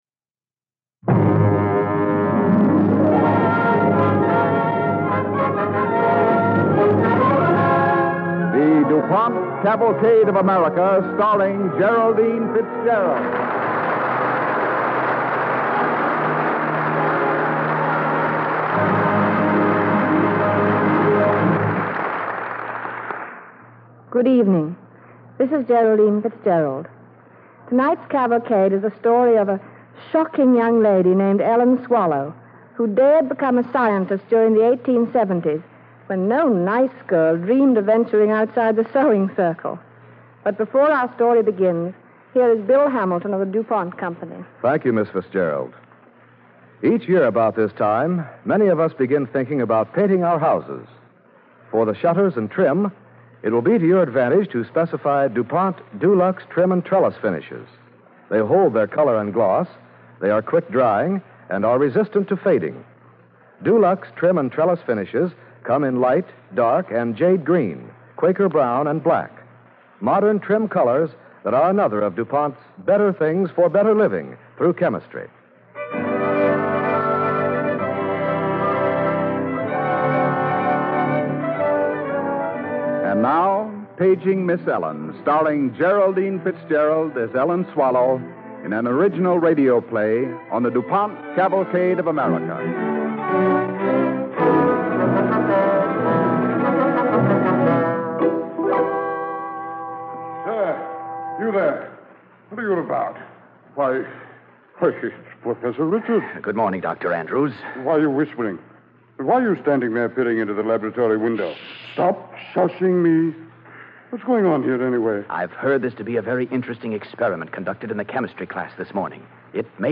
starring Geraldine Fitzgerald